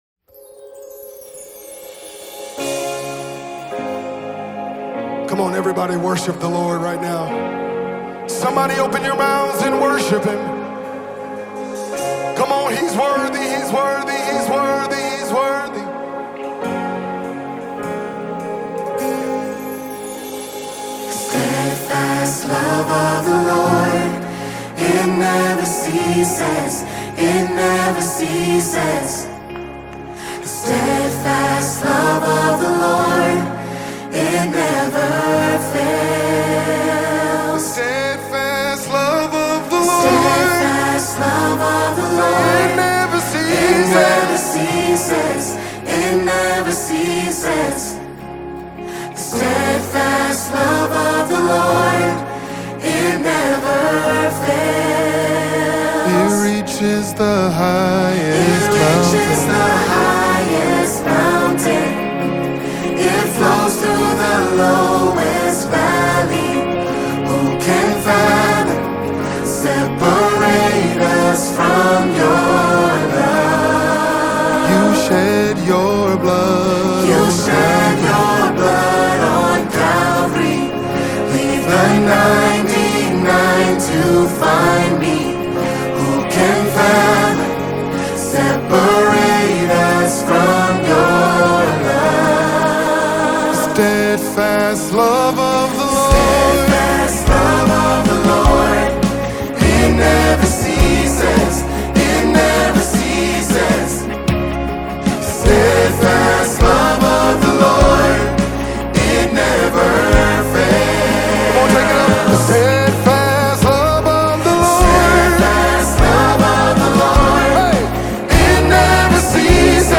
Mp3 Gospel Songs
is a heartfelt worship anthem
is a soulful and emotive ballad